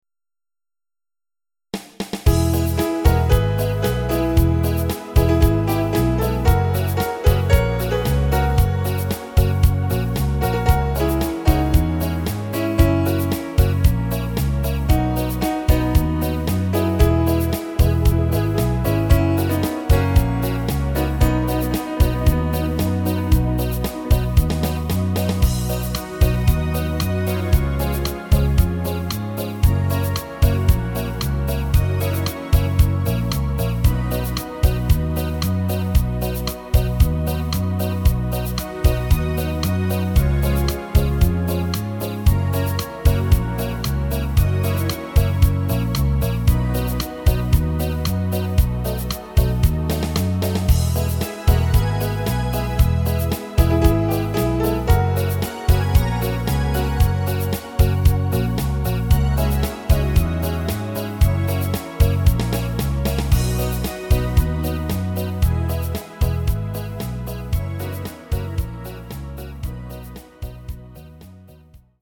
Rubrika: Pop, rock, beat
HUDEBNÍ PODKLADY V AUDIO A VIDEO SOUBORECH
vyhrávka piano: